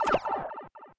Sound effect of Pipe Floor Enter (World Map) in Super Mario Bros. Wonder
SMBW_Pipe_Floor_Enter_World_Map.oga